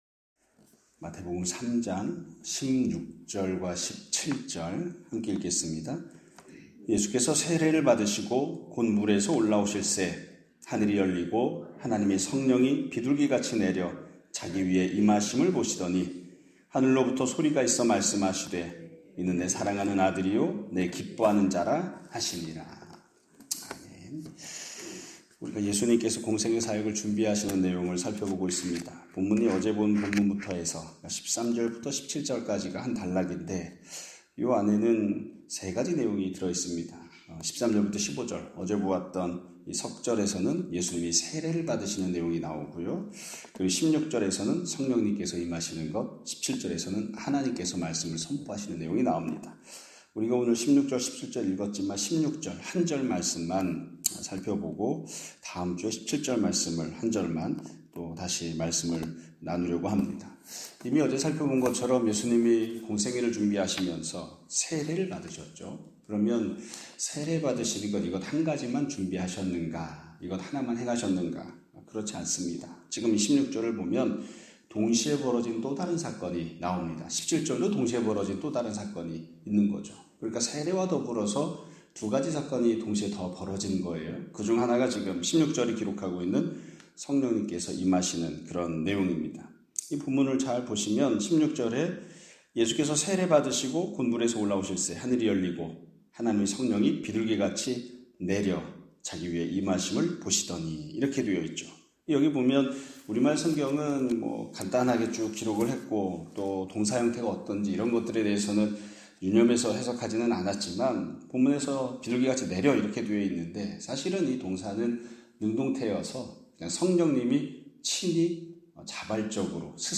2025년 4월 18일(금요일) <아침예배> 설교입니다.